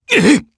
Gladi-Vox_Damage_jp_01.wav